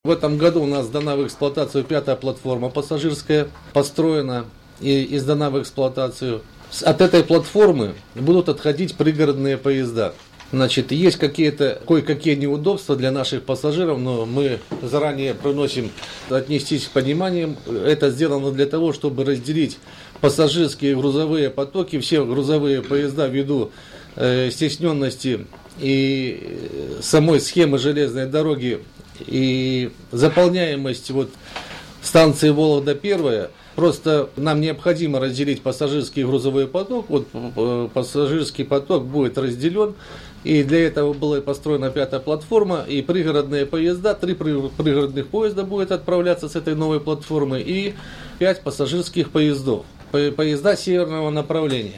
на пресс-конференции по летним пассажирским перевозкам